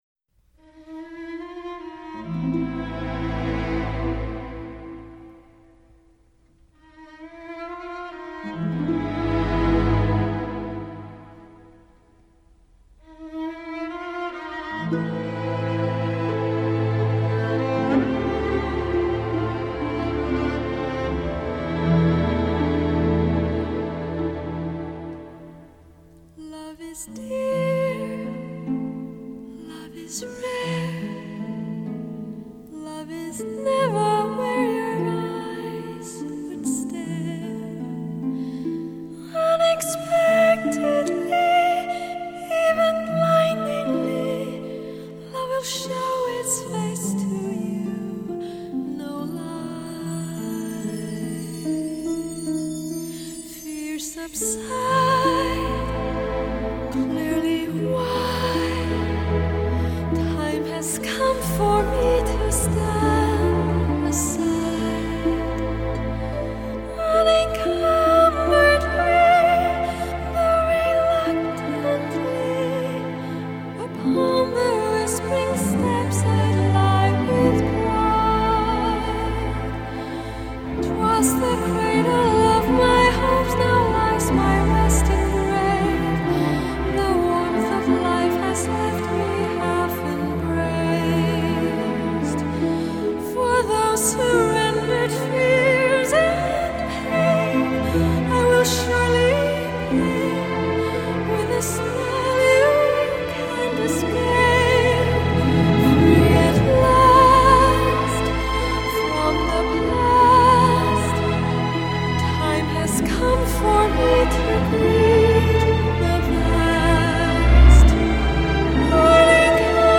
类别:电影原声